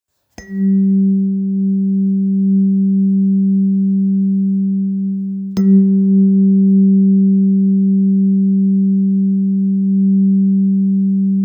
Vážená terapeutická  ladička 1. čakra kořenová /Muladhara/ ZÁKLAD 194,18 Hz
Zvuk trvá déle, ale je měkčí a více rozptýlený.
Laděna v mezích odchylky ±0,25 %.
Materiál: hliník
Zvuková ukázka vážená ladička Kořenová čakra (wav, 987 kB)